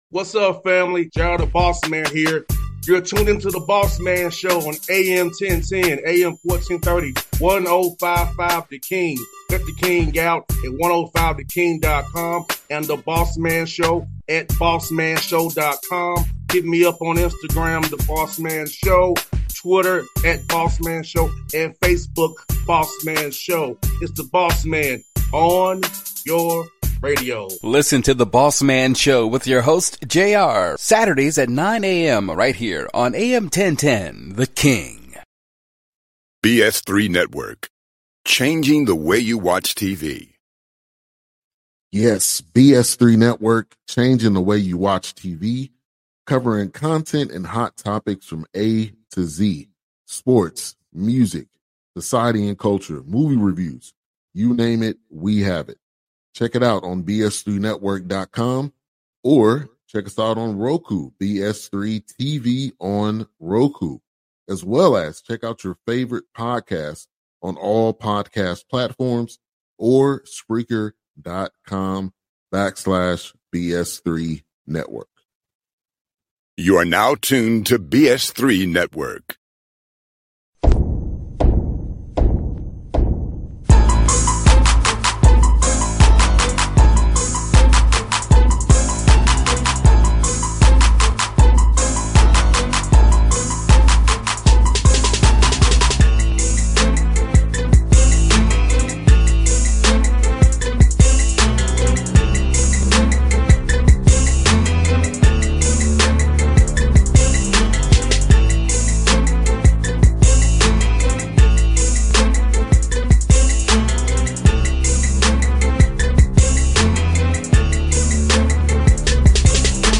Eddie George Interview